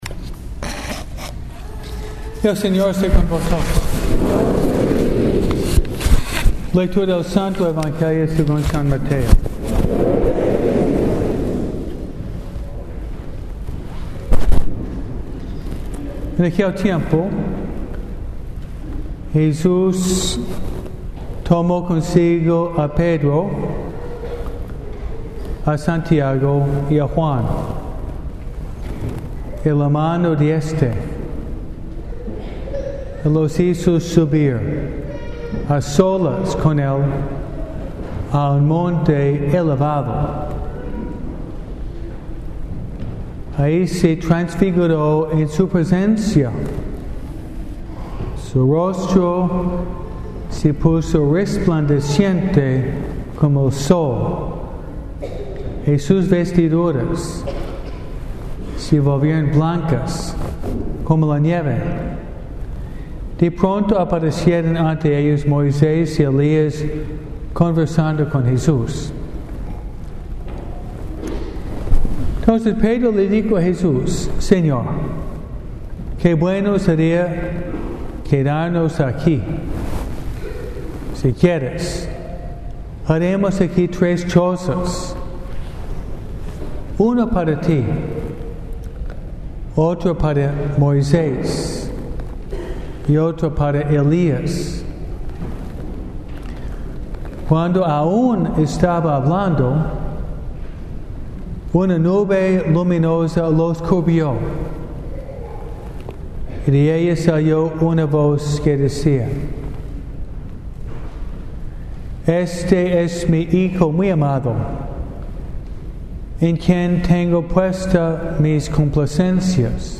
MISA – AMISTAD CON JESUS